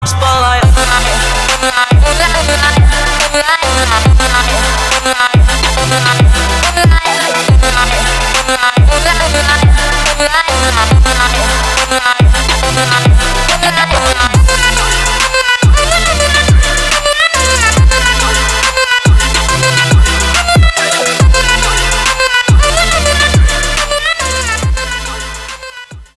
Электроника # без слов